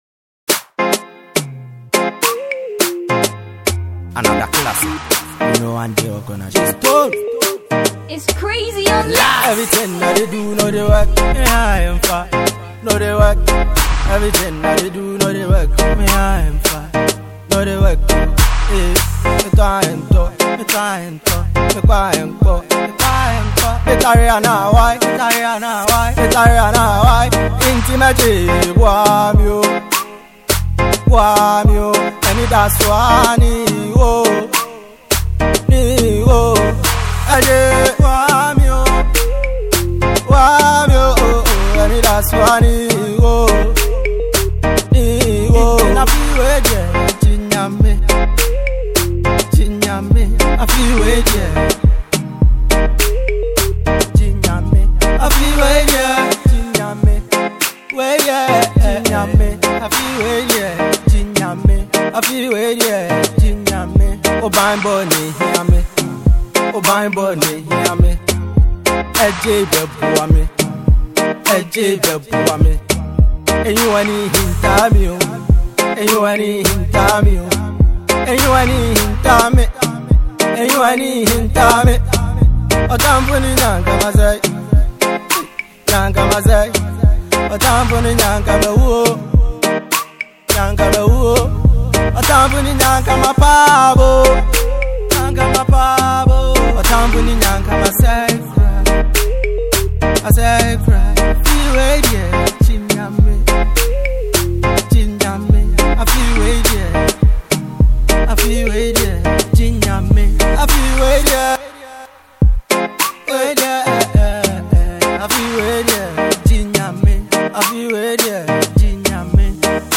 Gospel banger